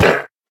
Minecraft Version Minecraft Version snapshot Latest Release | Latest Snapshot snapshot / assets / minecraft / sounds / mob / llama / hurt1.ogg Compare With Compare With Latest Release | Latest Snapshot
hurt1.ogg